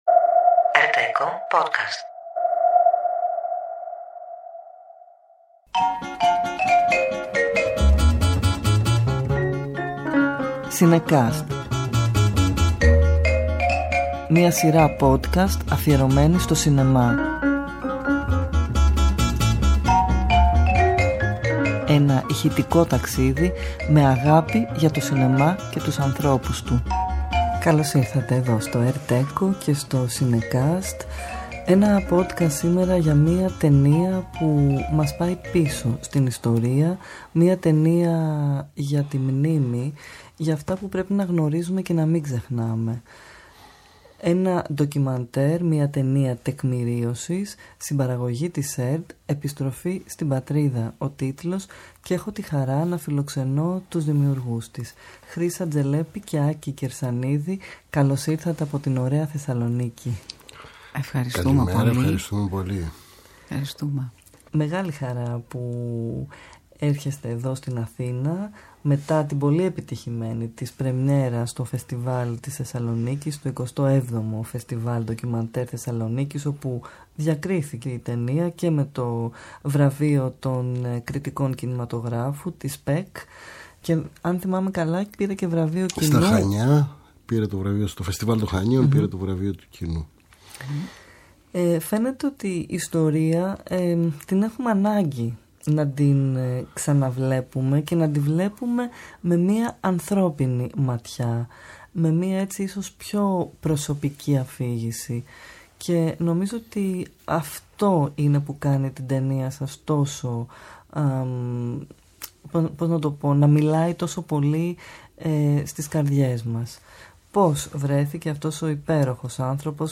Κάθε επεισόδιο ζωντανεύει μια ταινία μέσα από συνεντεύξεις, αποσπάσματα και μουσικές.